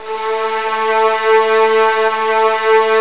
Index of /90_sSampleCDs/Roland LCDP13 String Sections/STR_Violins I/STR_Vls2 Arco